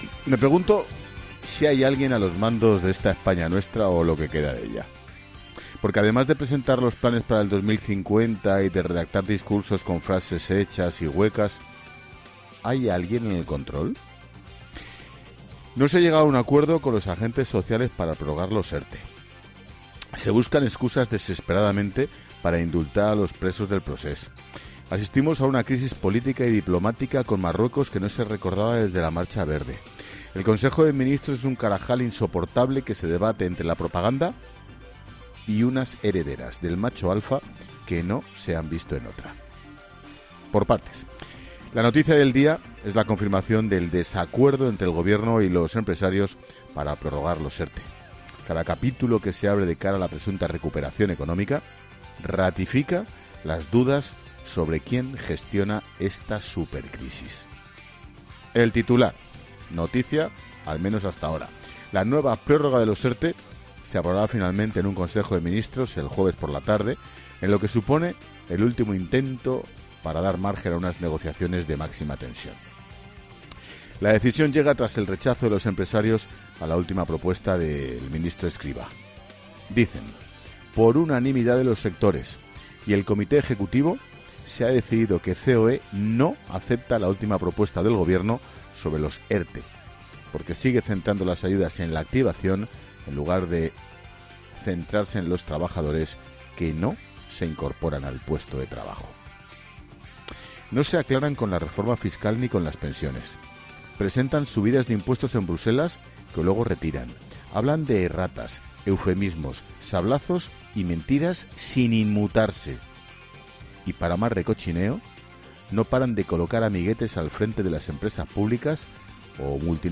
Monólogo de Expósito
El director de 'La Linterna', Ángel Expósito, reflexiona en su monólogo sobre las principales noticias que deja este martes